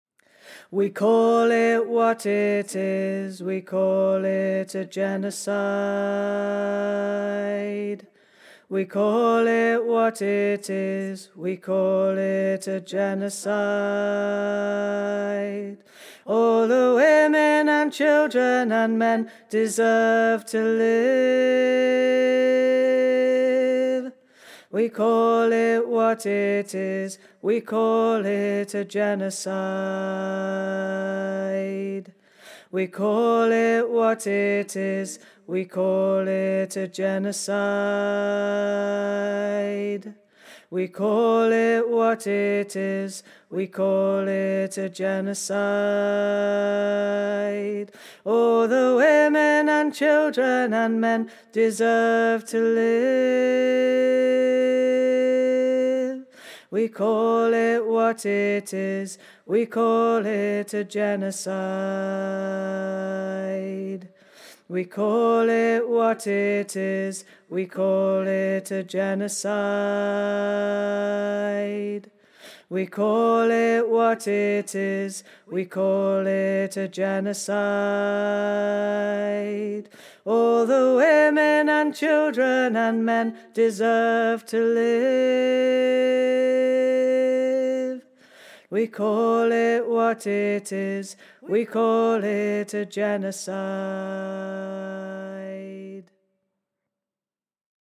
A street song